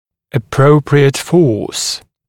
[ə’prəuprɪət fɔːs][э’проуприэт фо:с]надлежащая сила, надлежащее усилие